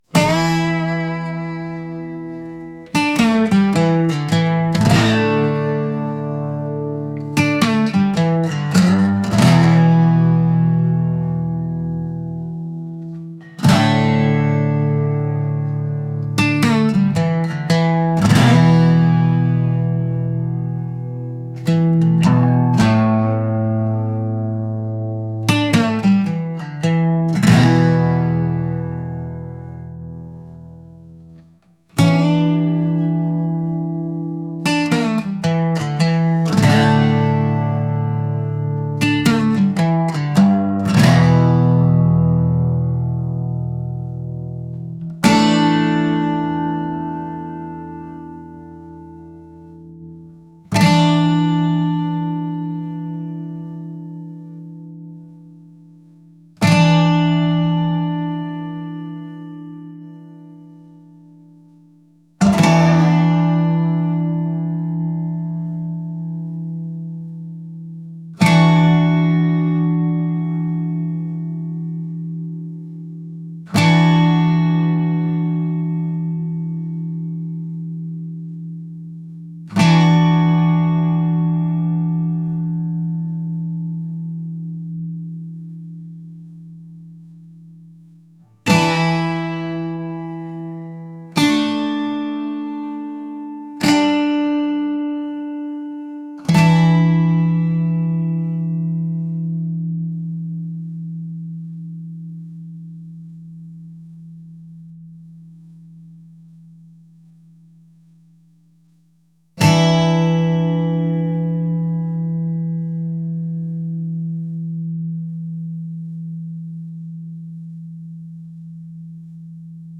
soul | blues